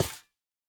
Minecraft Version Minecraft Version snapshot Latest Release | Latest Snapshot snapshot / assets / minecraft / sounds / block / spawner / step5.ogg Compare With Compare With Latest Release | Latest Snapshot
step5.ogg